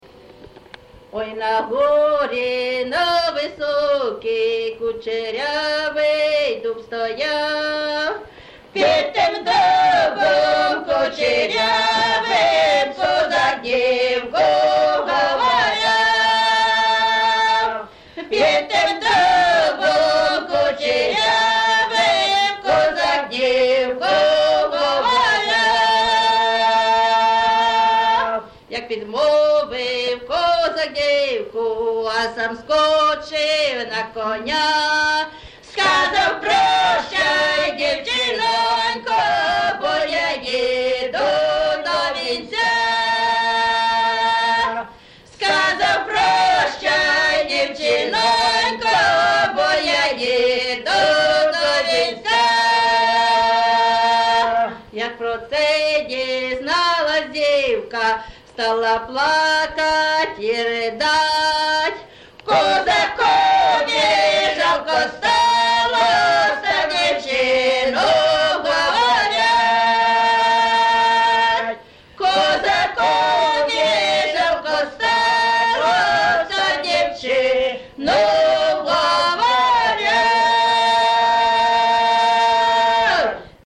ЖанрПісні з особистого та родинного життя
Місце записус. Яблунівка, Костянтинівський (Краматорський) район, Донецька обл., Україна, Слобожанщина